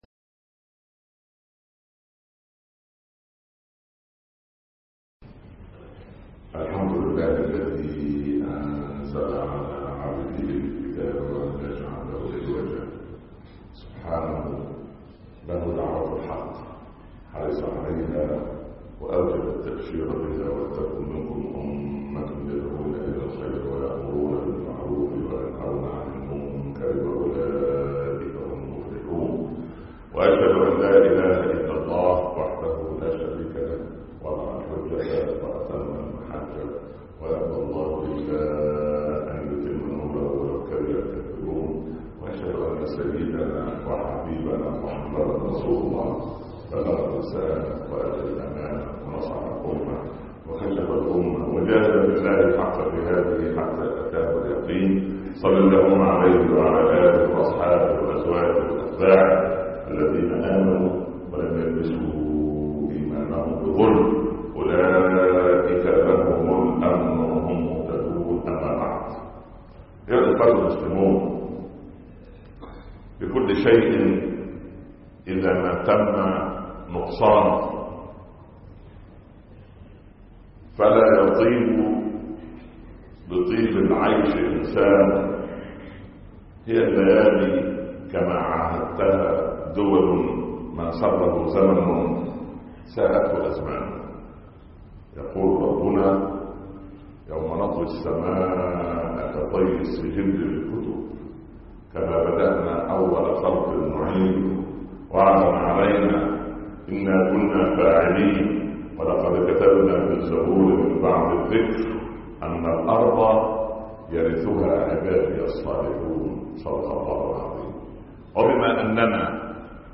طه وعمر( 25/7/2014) خطب الجمعة - الشيخ عمر بن عبدالكافي